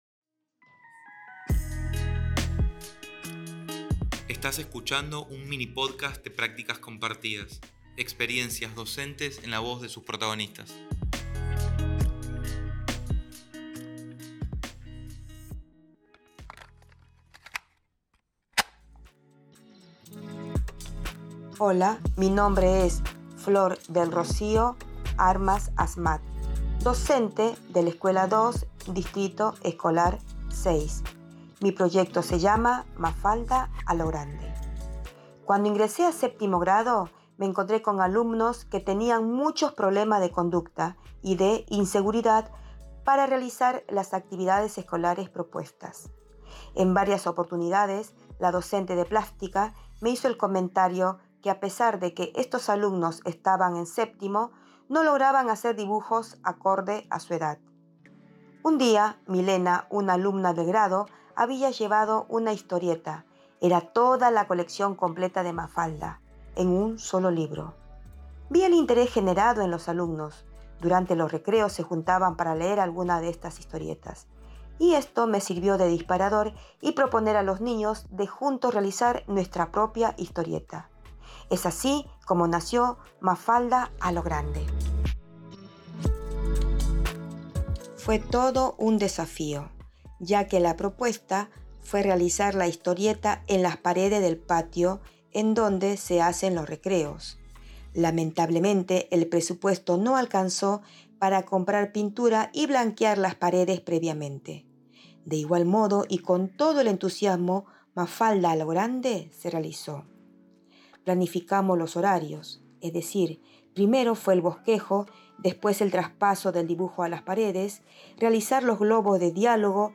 Podcast: en palabras de las docentes